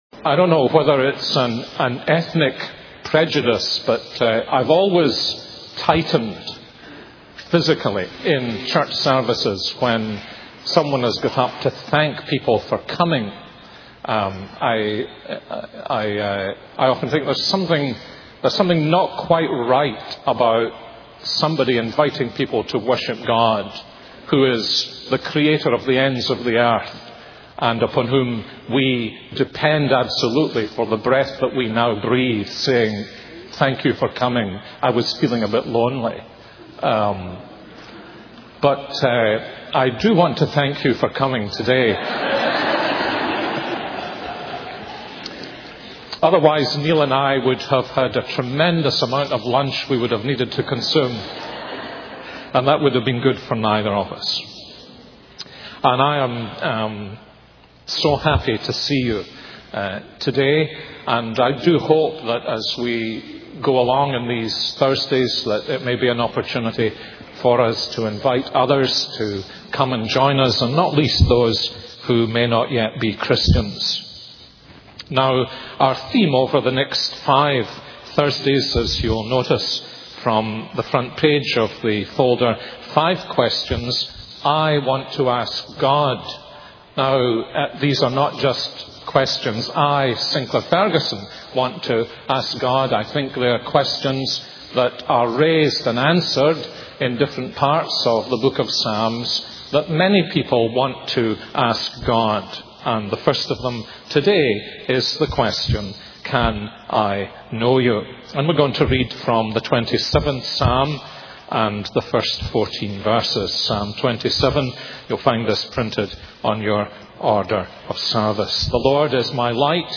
This is a sermon on Psalm 27:1-14.